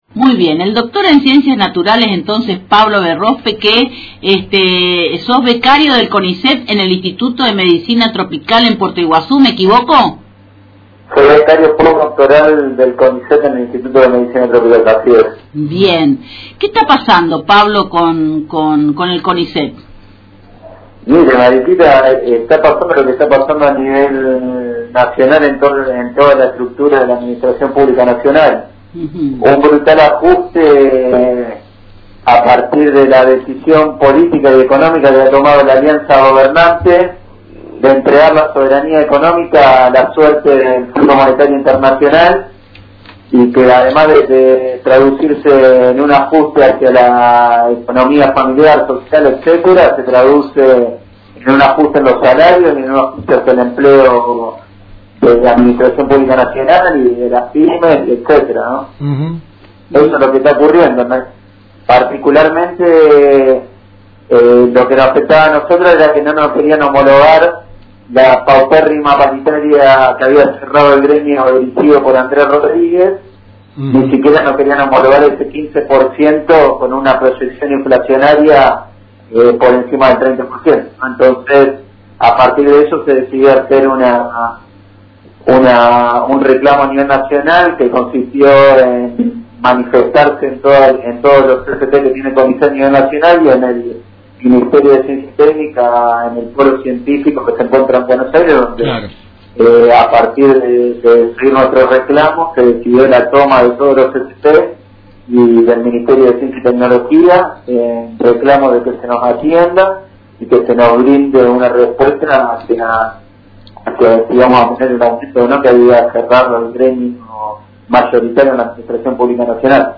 entrevistado en el programa Contala como quieras